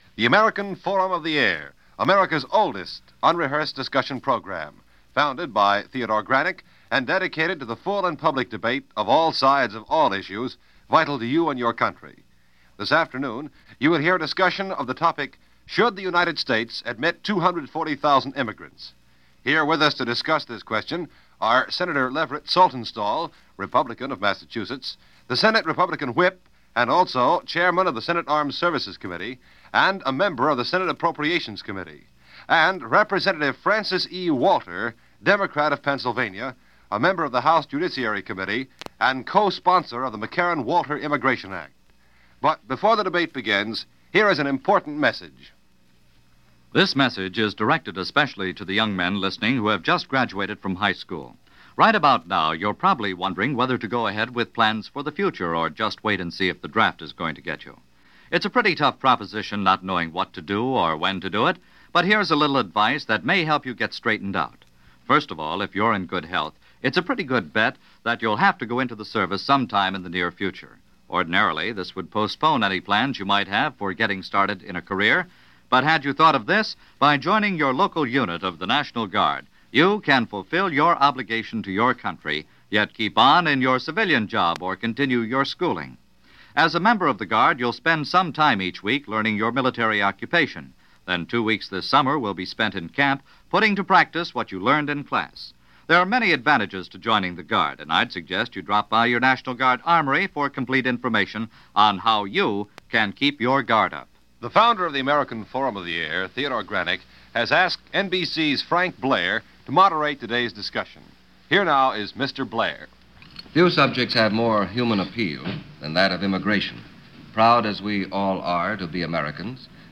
And so the issue of whether or not the U.S. could (or would) allow some 240,000 immigrants into the country was the stuff of debate, in this episode of American Forum Of The Air. The immigrants in question were all to come from the Iron Curtain countries of Eastern Europe.